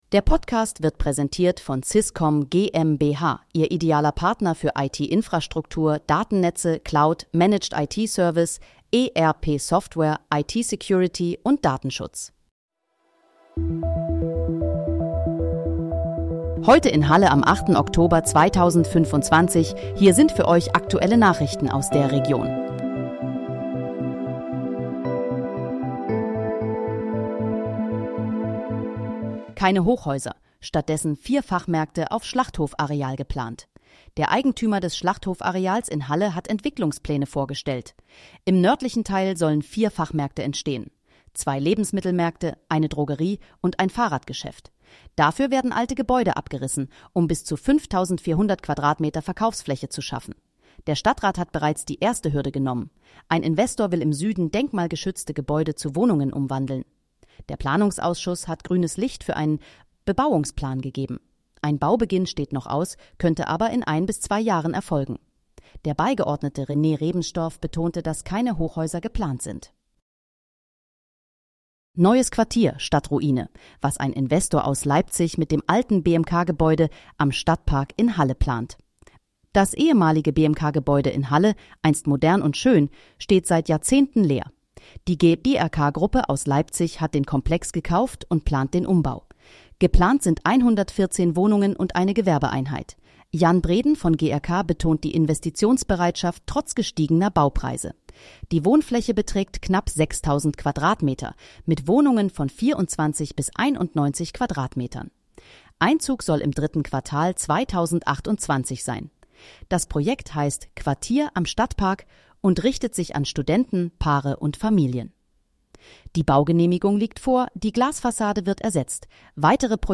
Heute in, Halle: Aktuelle Nachrichten vom 08.10.2025, erstellt mit KI-Unterstützung
Nachrichten